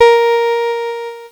Cheese Note 04-A#2.wav